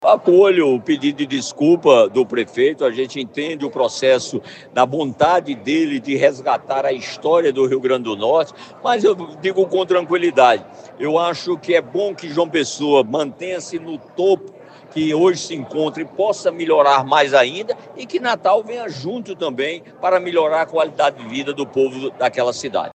Os comentários do prefeito foram registrados pelo programa Correio Debate, da 98 FM, de João Pessoa, nesta quinta-feira (09/01).